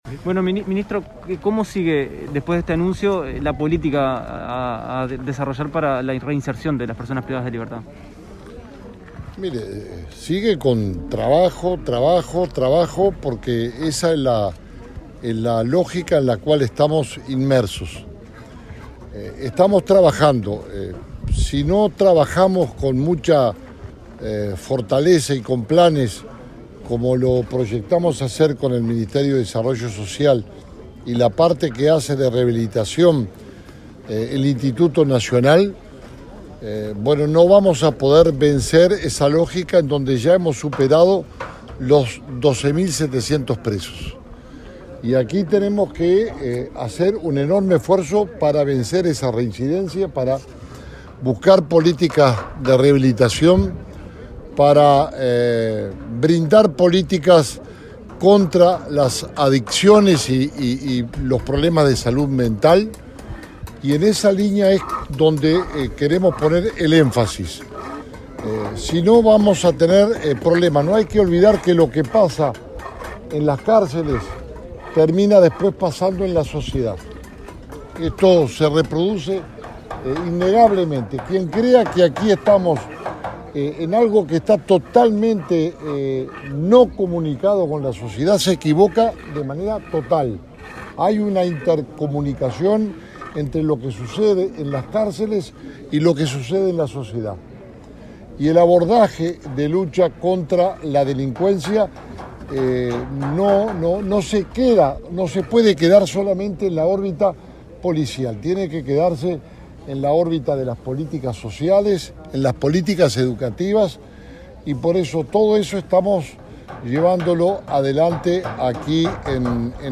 Tras la firma de un acuerdo con el Mides para la instalación de una oficina de ese ministerio en el Comcar, el ministro Jorge Larrañaga destacó que si no se trabaja con este tipo de planes para la rehabilitación, no se vencerá la lógica de reincidencia de delito de quienes salen de los centros de reclusión.